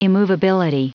Prononciation du mot immovability en anglais (fichier audio)
Prononciation du mot : immovability